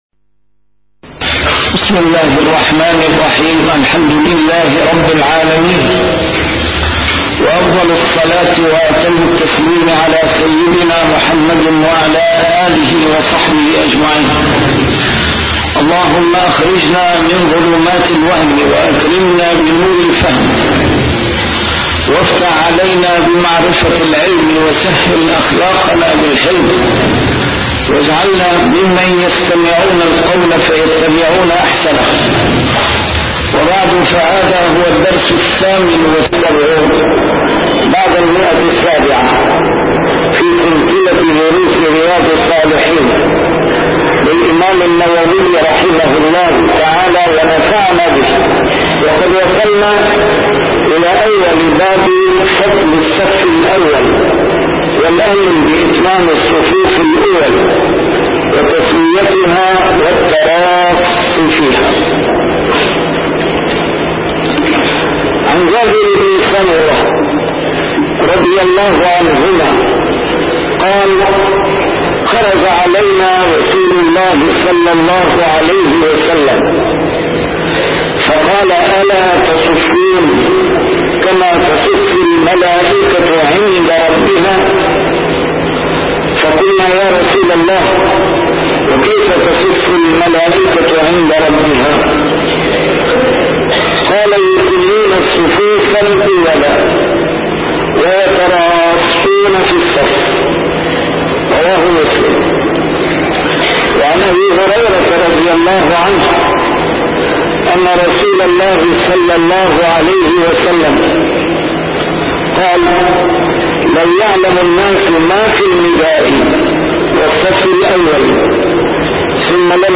نسيم الشام › A MARTYR SCHOLAR: IMAM MUHAMMAD SAEED RAMADAN AL-BOUTI - الدروس العلمية - شرح كتاب رياض الصالحين - 778- شرح رياض الصالحين: فضل الصف الأول